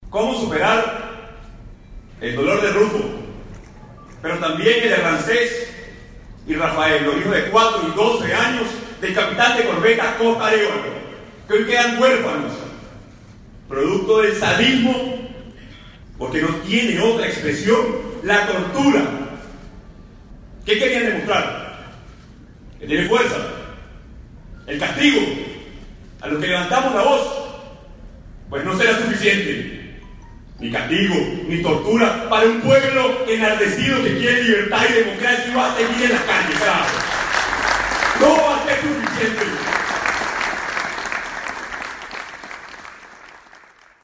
Al calor de su denuncia, el presidente interino no pudo contener una palabrota.
Guaidó enfurecido en plena sesión de la Asamblea Nacional